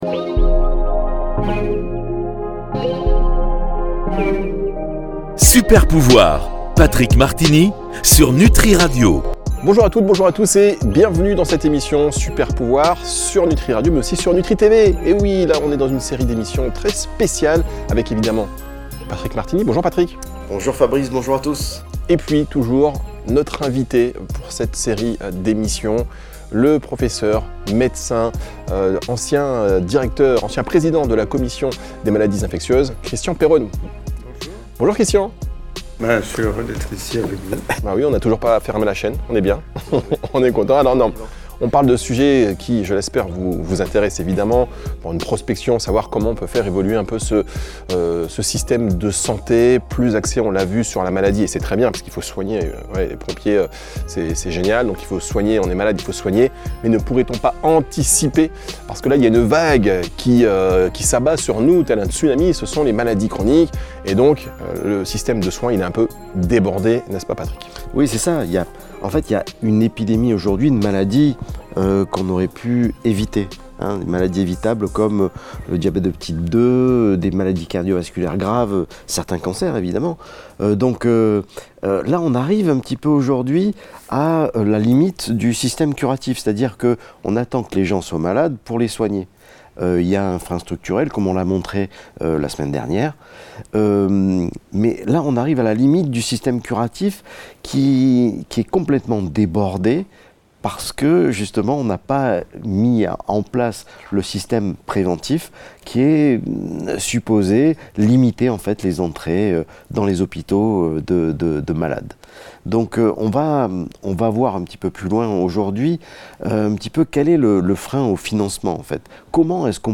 Invité: Christian Perronne. Comment redéfinir le système de santé.
3em et dernière partie de cette émission passionnante avec le dr Christian Perronne.